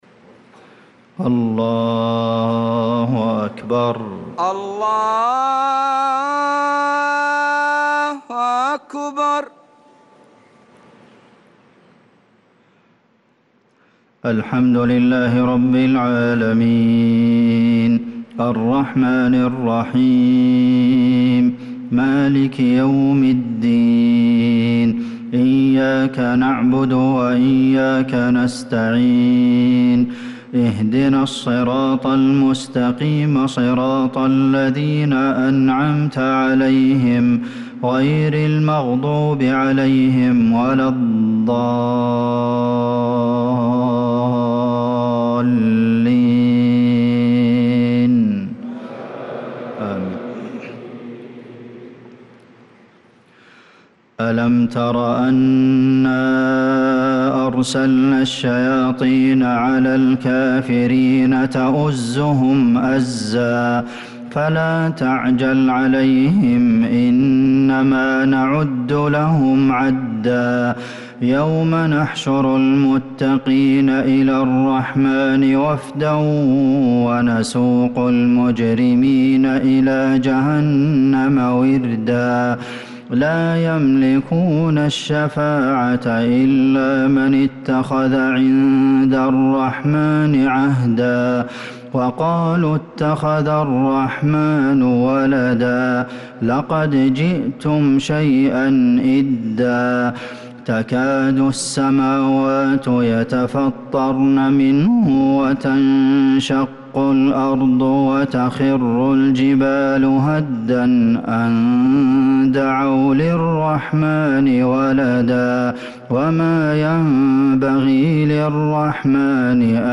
عشاء الأحد 5-3-1446هـ خواتيم سورة مريم | Isha prayer from Surah Maryam 8-9-2024 > 1446 🕌 > الفروض - تلاوات الحرمين